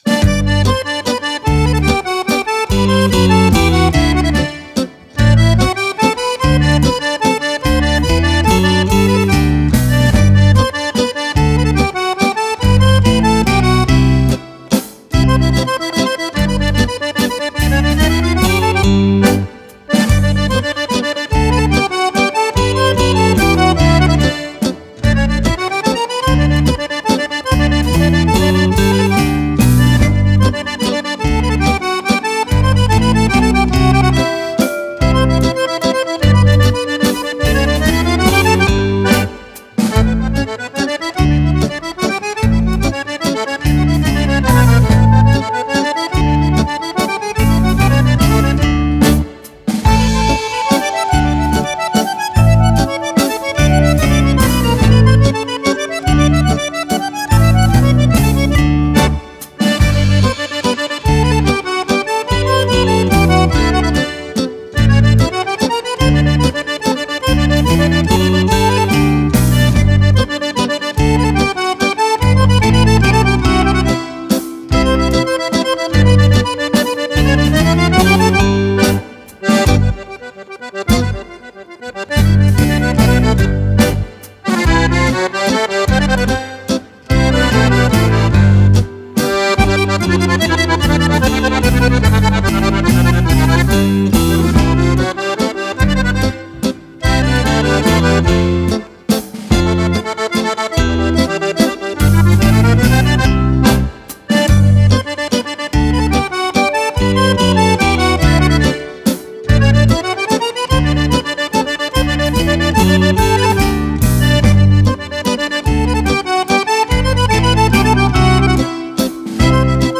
Mazurka
Mazurka per Fisarmonica